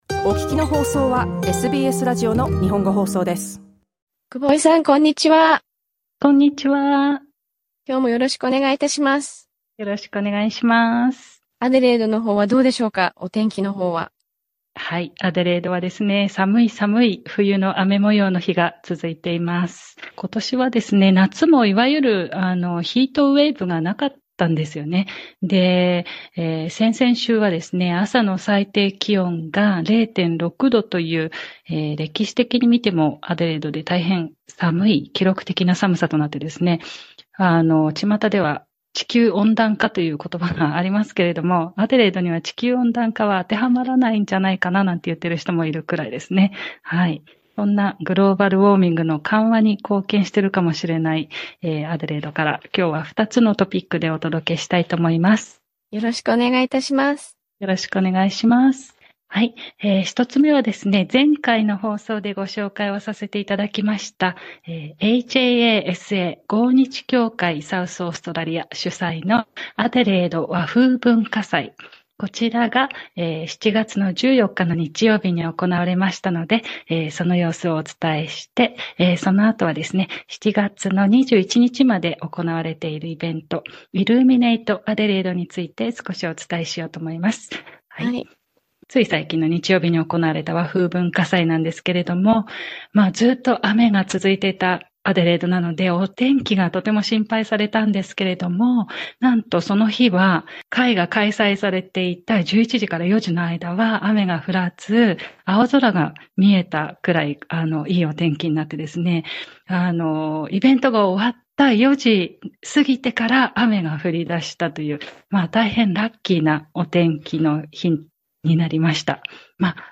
国内各地の話題や情報をお伝えするコーナー、「オーストラリアワイド」。